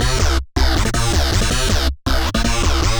Index of /musicradar/future-rave-samples/160bpm